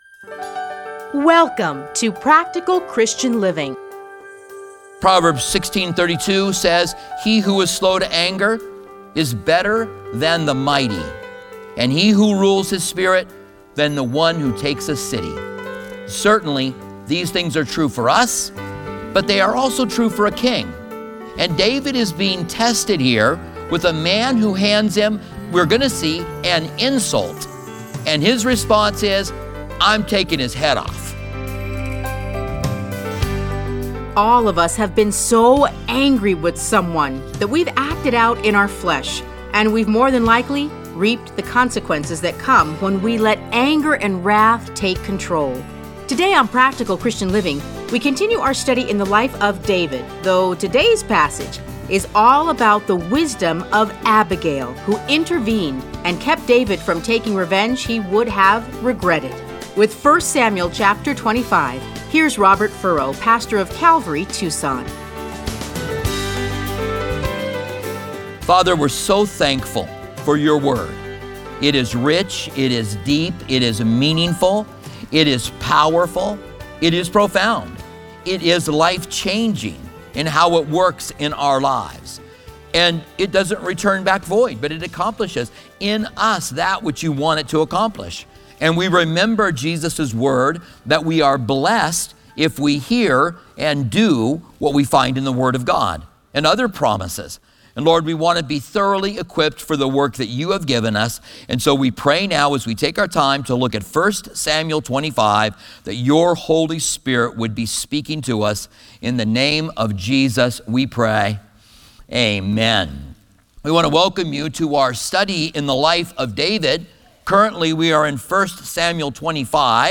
Listen to a teaching from 1 Samuel 25:1-44.